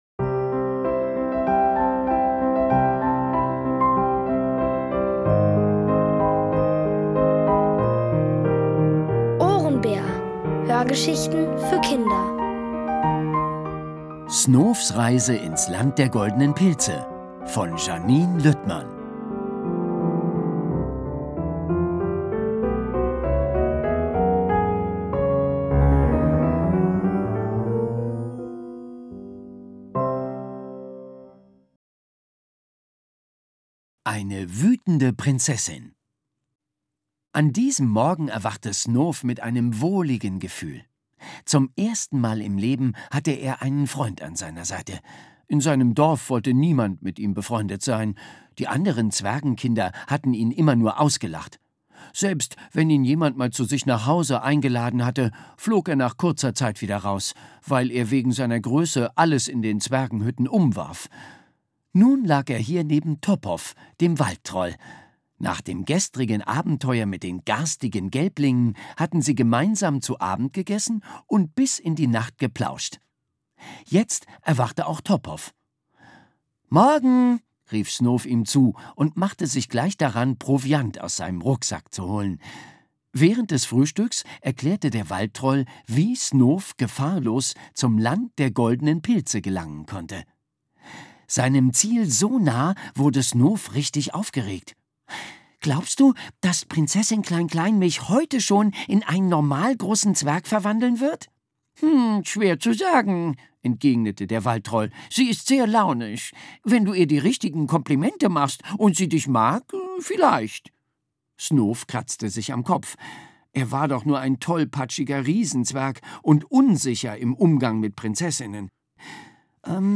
Aus der OHRENBÄR-Hörgeschichte: Snoofs Reise ins Land der goldenen Pilze (Folge 6 von 7) von Janine Lüttmann.